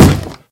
sounds / mob / zombie / wood1.ogg
wood1.ogg